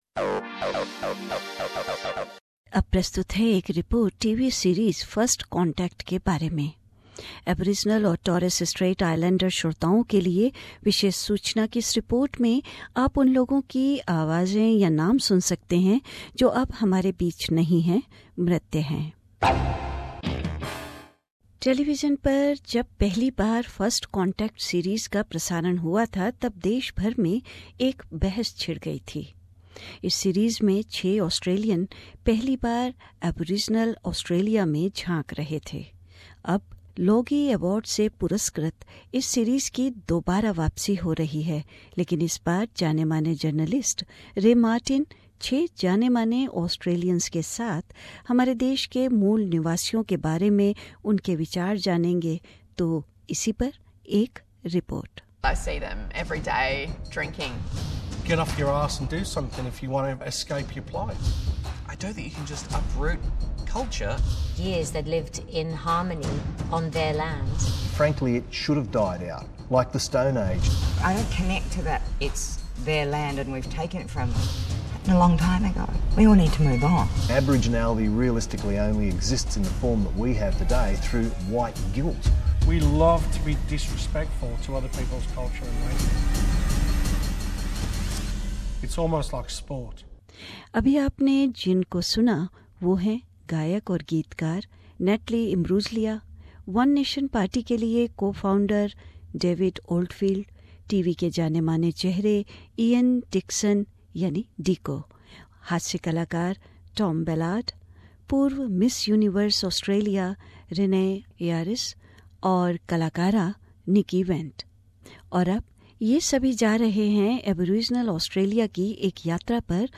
एक चेतावनी एबोरीजनल और टोरस स्ट्रेट आयलेन्डर श्रोताऔ के लिये - इस रिपोर्ट में आप उन लेोगों की आवाजें या नाम सुन सकते हैं जो अब मृत्य हैं।